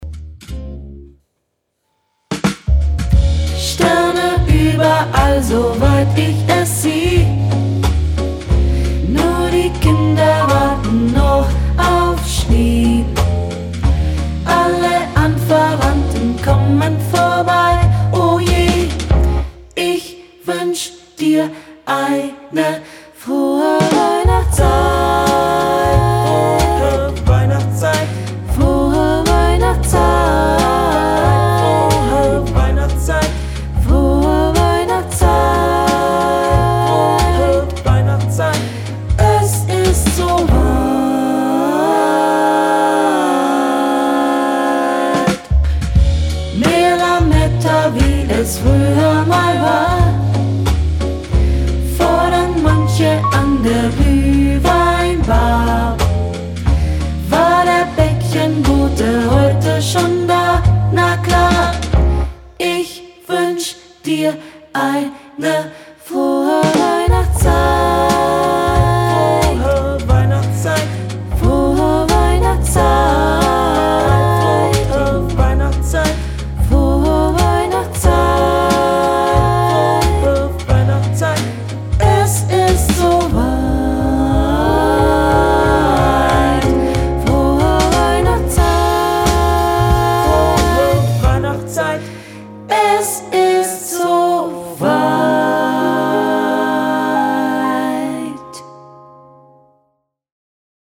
Frohe_Weihnachtszeit - Chor.mp3